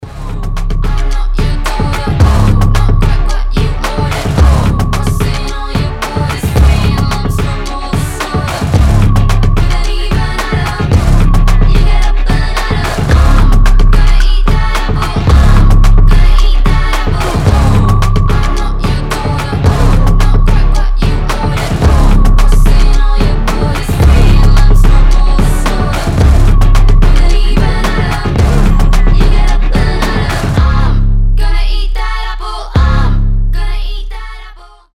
• Качество: 320, Stereo
ритмичные
басы
indie pop
alternative
indie rock
бодрые
озорные
Electropop